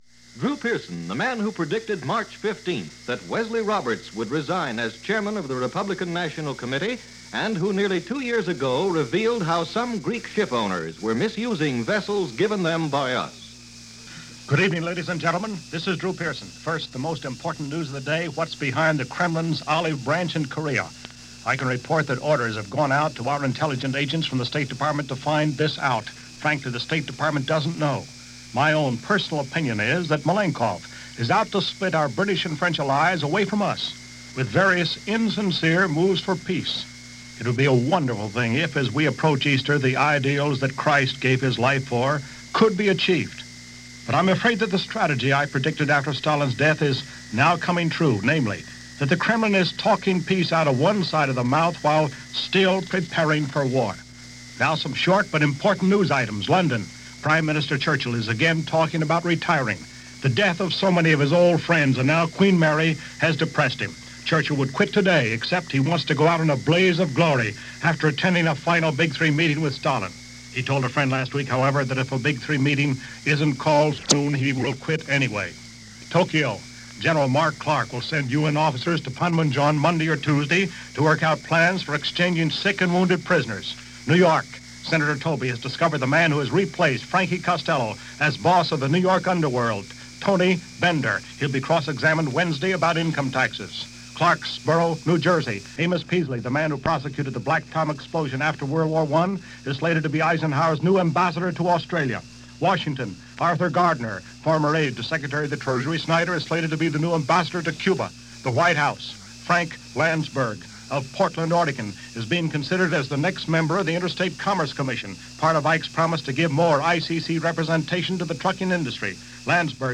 Cold War Muckraking - Korea - The Kremlin And Frank Costello - March 29, 1953 - Drew Pearson News And Commentary - ABC Radio.